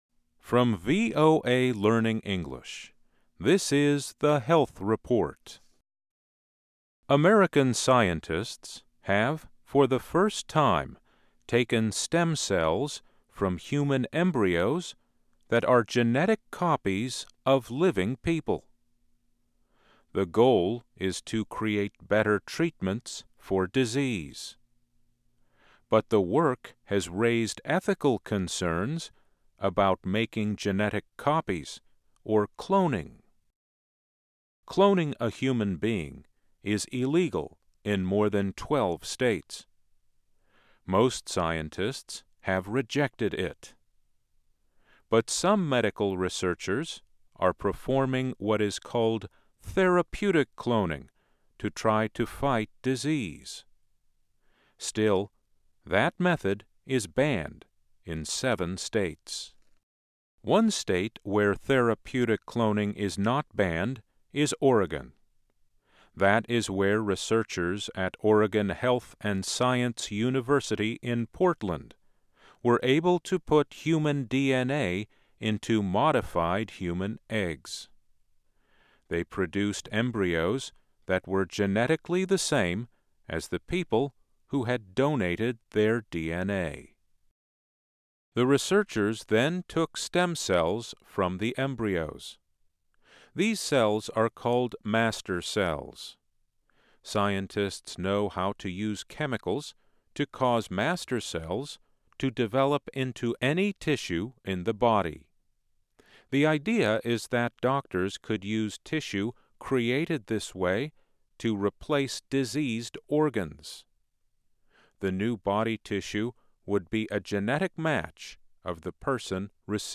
VOA健康报道-科学家首次通过克隆获取人类干细胞|VOA慢速英语